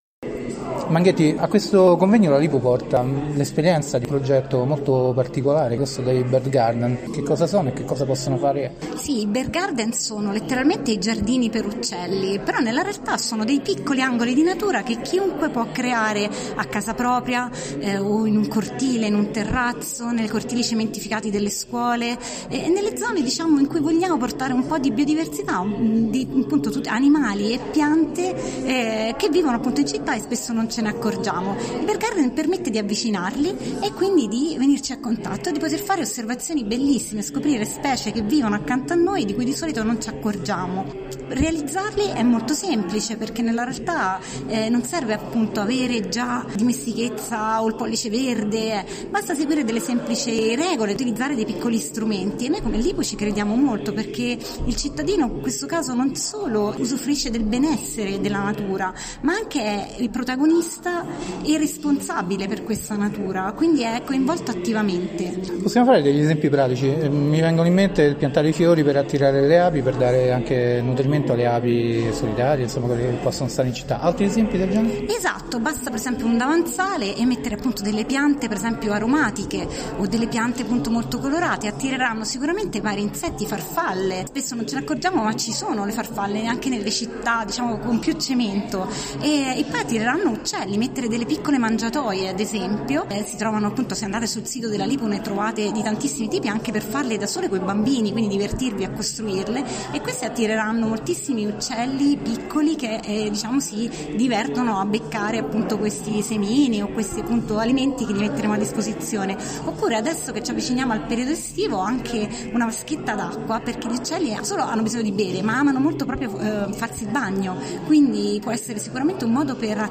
Ecosistema Educazione Interviste